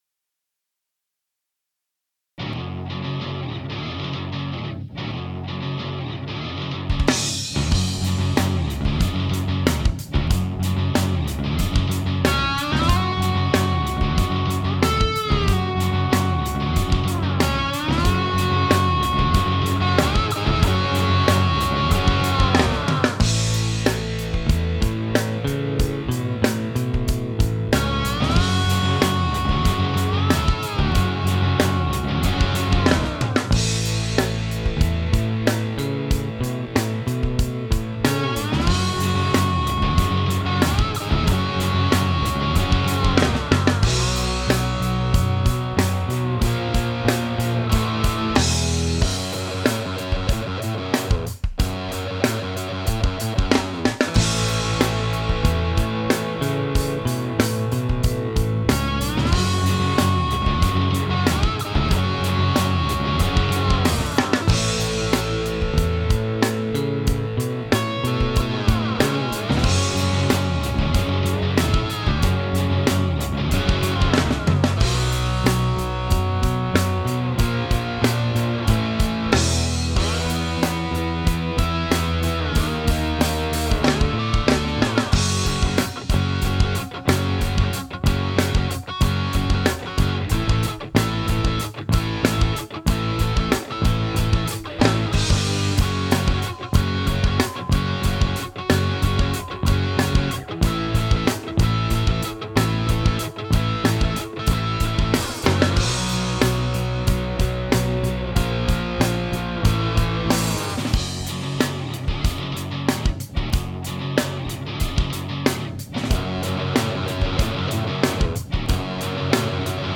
I want to see if i can do something about the stiffness of the sound.
For this tune, I used an "Angus Marshall" setting for my rythm guitars, and a "Clapton Sunshine Love" setting for the slide parts.
That's my bass through some Ozone mangler of some kind.